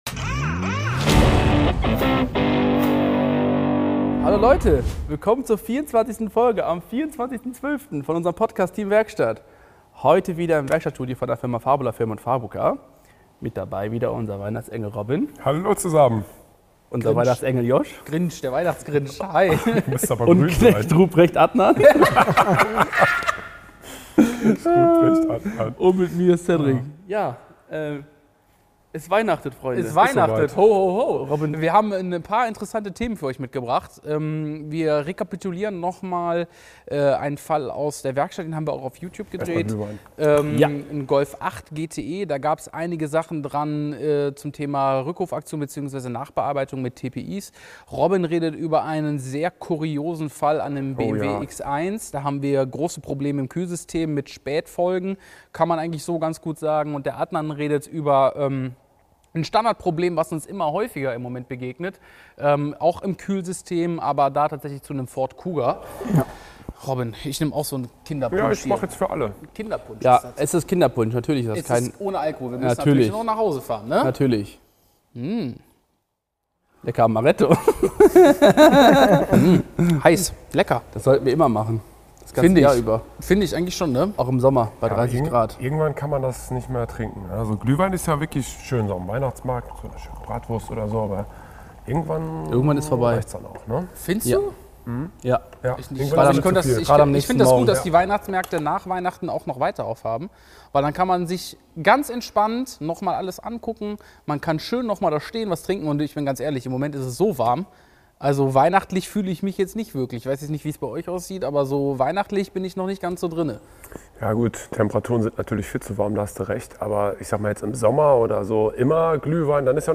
:-) Wir haben es uns im Werkstattstudio gemütlich gemacht und für euch eine ganz besondere Weihnachtsfolge aufgenommen.